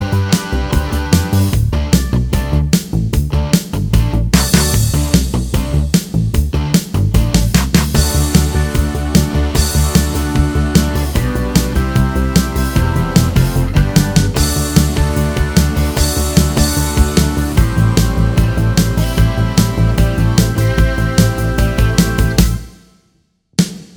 Minus Lead Guitars Pop (1980s) 3:55 Buy £1.50